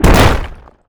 rock_impact_spike_trap_03.wav